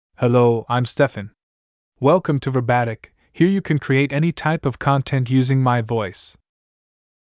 Steffan — Male English (United States) AI Voice | TTS, Voice Cloning & Video | Verbatik AI
MaleEnglish (United States)
Steffan is a male AI voice for English (United States).
Voice sample
Male
Steffan delivers clear pronunciation with authentic United States English intonation, making your content sound professionally produced.